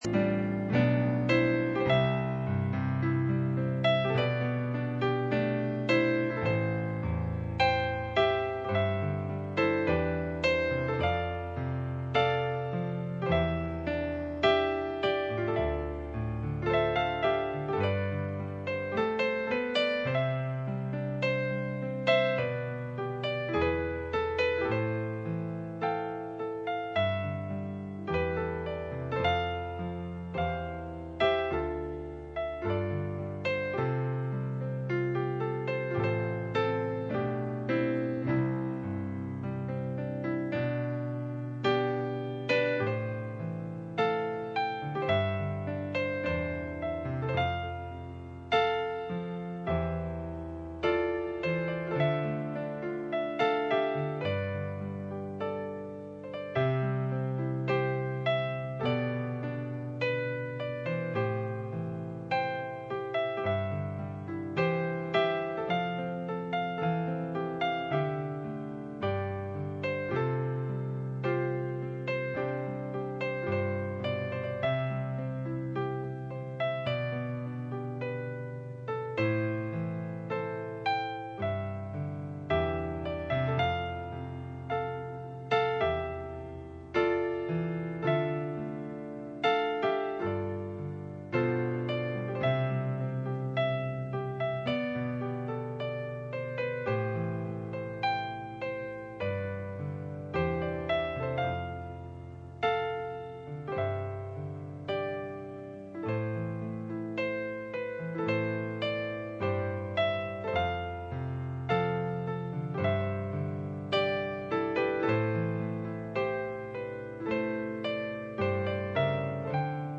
Isaiah 59:19 Service Type: Friday Night %todo_render% « Church Ages